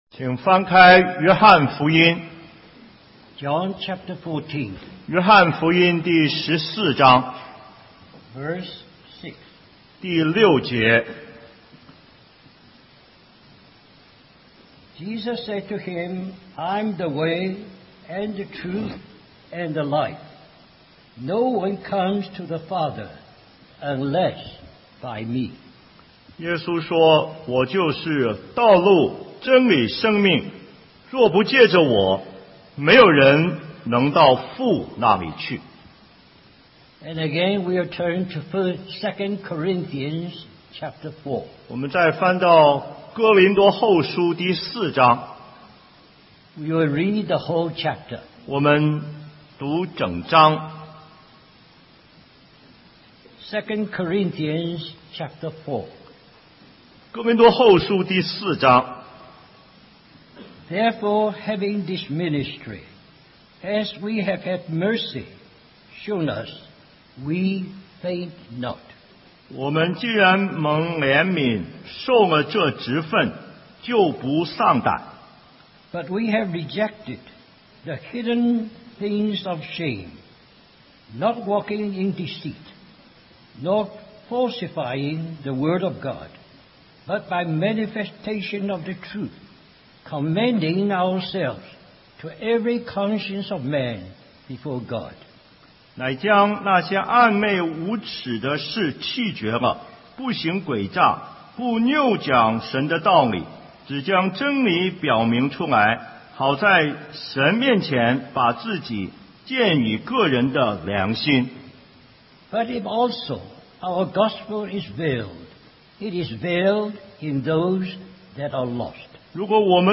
2009 Special Conference For Service, Singapore Stream or download mp3 Summary Our brother shares from the conference theme of "Christ Centered Service".